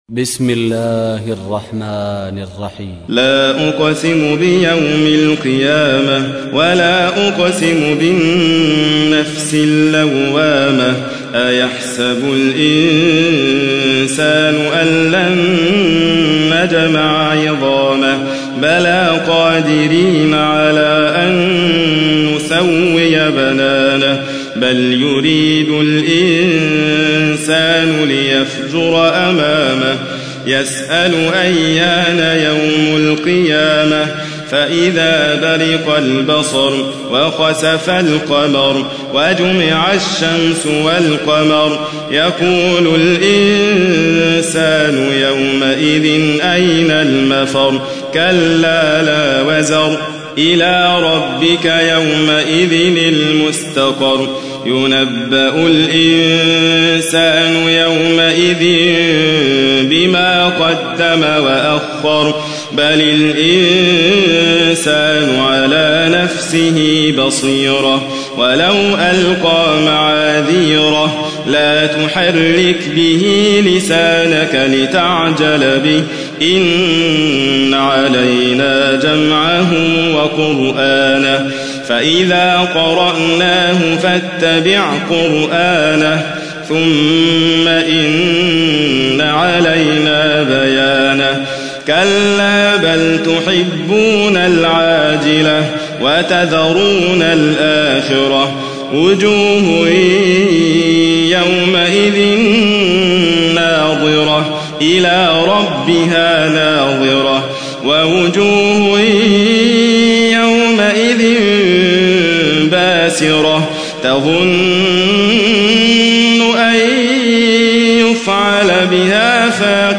تحميل : 75. سورة القيامة / القارئ حاتم فريد الواعر / القرآن الكريم / موقع يا حسين